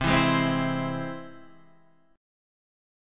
C Augmented
C+ chord {8 7 6 5 5 8} chord